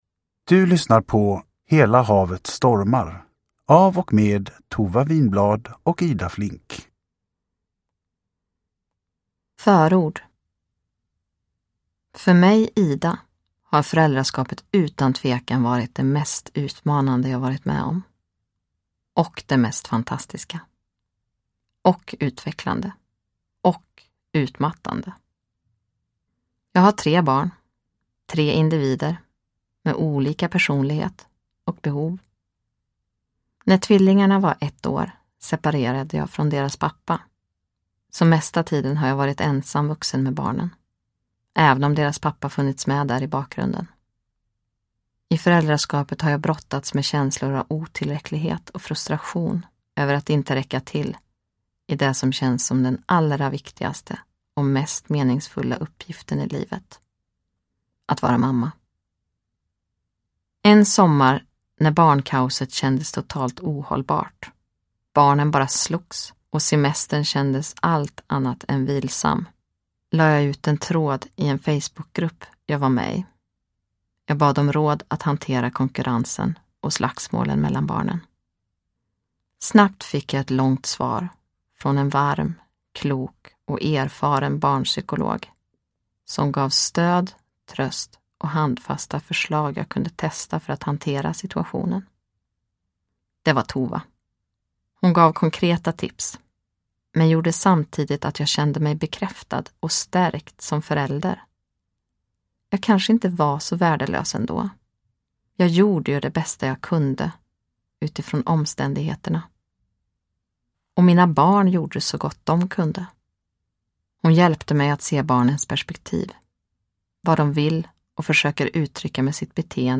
Hela havet stormar : småbarnsfamiljens livboj vid trots, konflikter och syskonbråk (ljudbok